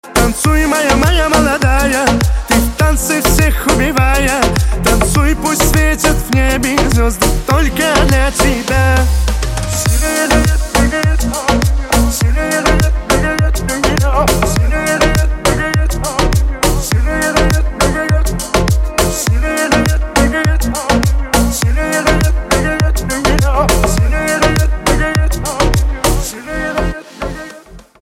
Кавказские Рингтоны
Поп Рингтоны